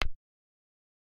click1.ogg